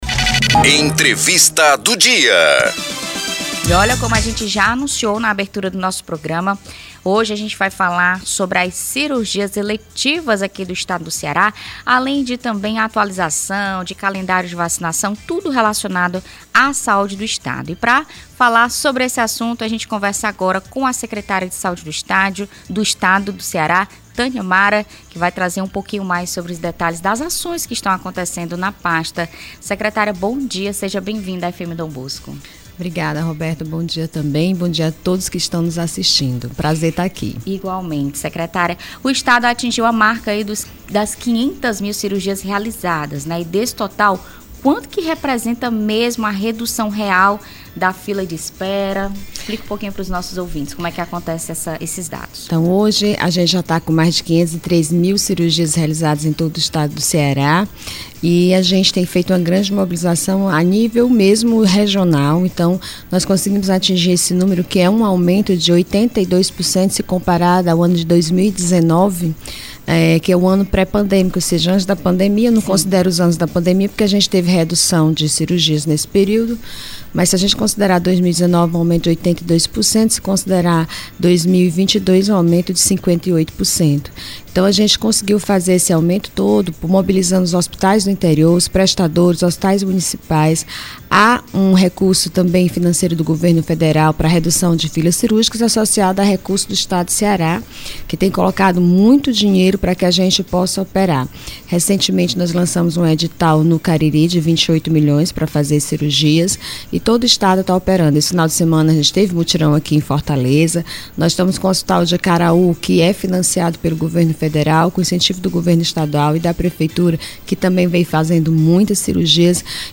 Secretária da Saúde aborda sobre cirurgias eletivas e situação da vacinação no Ceará; confira entrevista
O Informativo Dom Bosco desta segunda-feira (27/04) recebeu a secretária da Saúde do Estado do Ceará, Tânia Mara Coelho, para uma entrevista voltada aos principais temas que impactam diretamente a vida da população. Durante a conversa, foram abordadas as ações desenvolvidas pela Secretaria da Saúde, os avanços e desafios na realização de cirurgias eletivas e a importância das campanhas de vacinação.
ENTREVISTA_TANIA-MARA.mp3